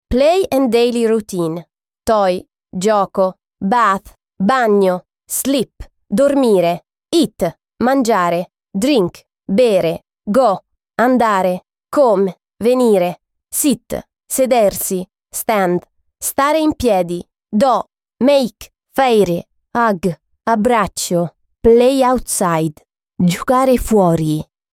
Lesson 4